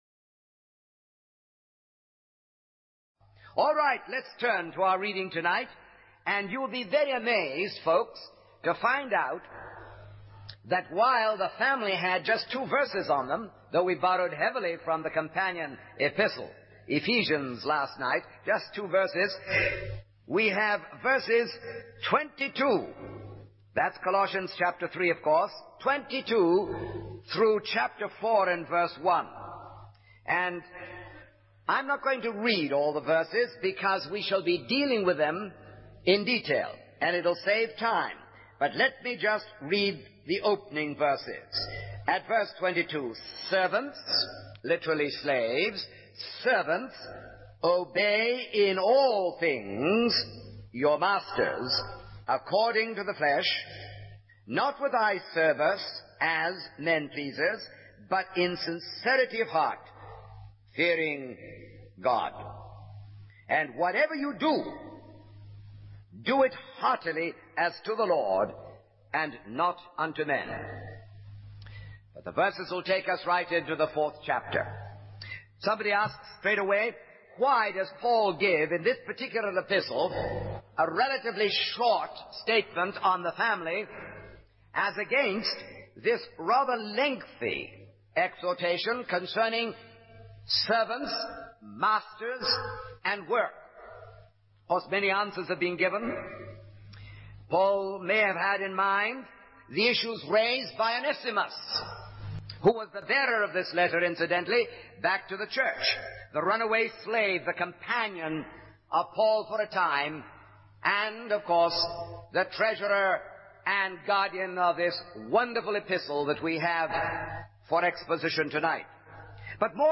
In this sermon, the speaker discusses the challenge of reaching an apathetic world with the gospel.